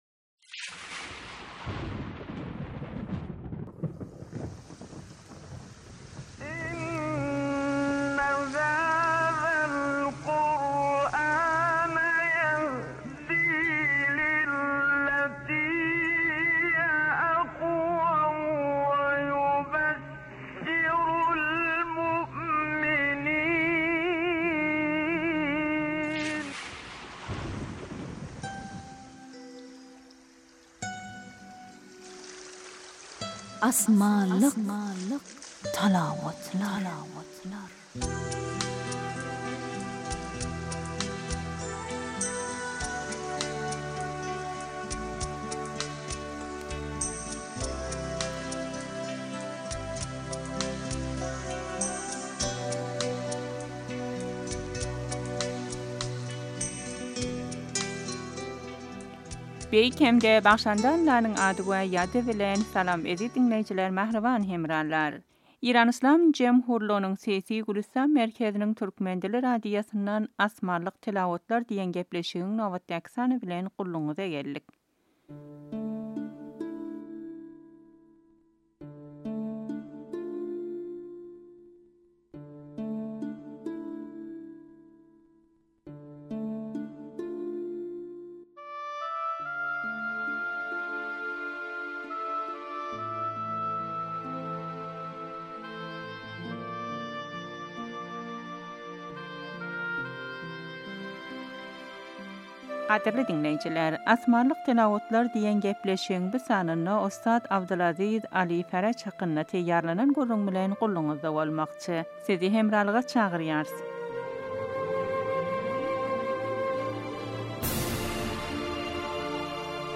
Asmanlik talawatlar